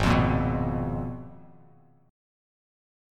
G#m6add9 chord